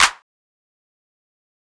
Tm8_Clap33.wav